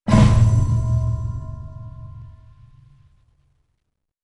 重大事件提示.wav